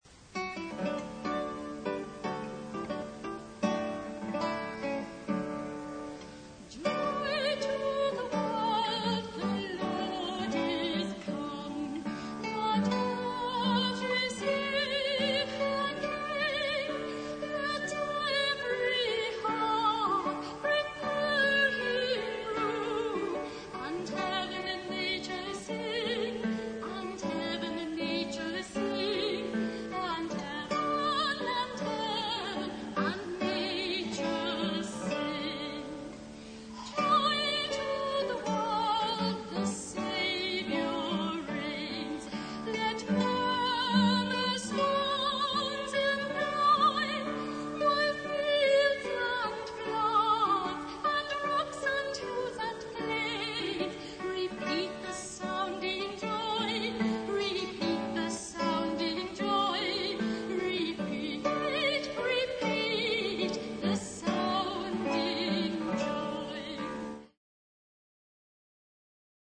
They are mostly live extracts from public performances.
(live extract)
for voice and guitar